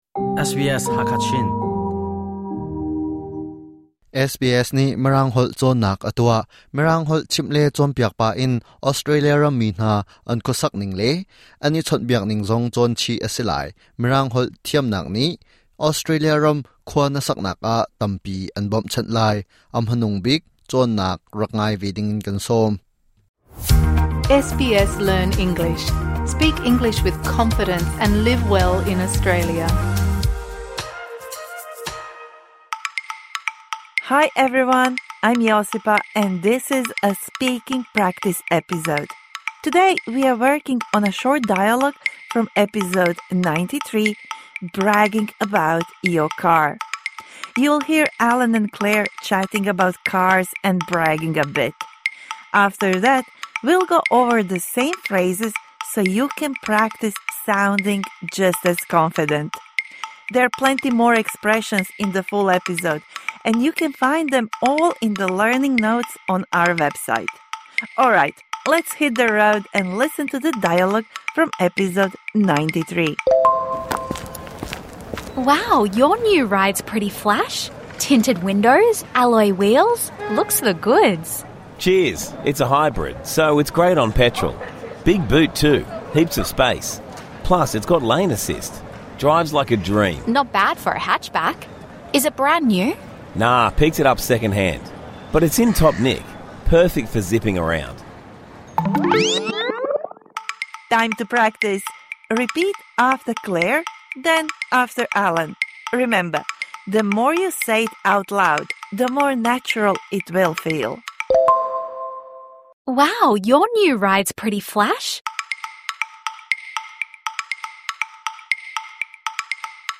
Speaking out loud will help to improve your English speaking fluency and will make it easier for you to remember new vocabulary. This bonus episode provides interactive speaking practice for the words and phrases you learnt in #93 Bragging about your car (Med).